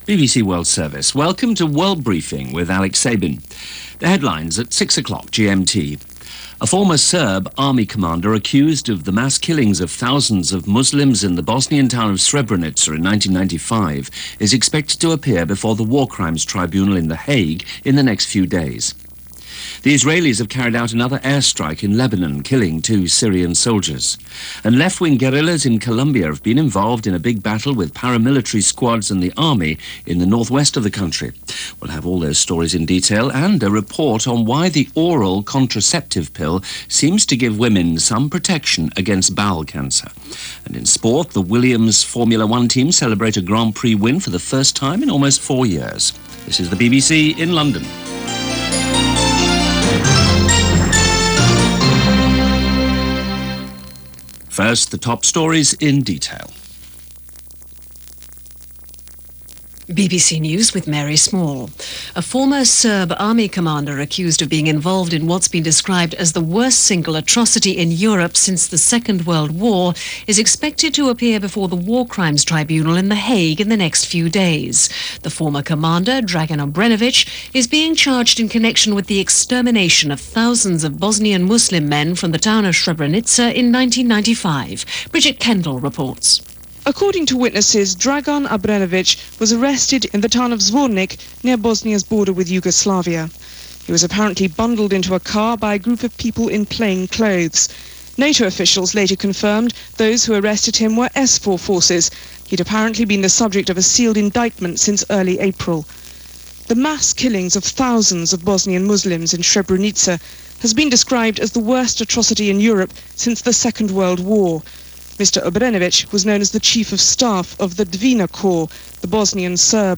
April 16, 2001 – News from the BBC World Service.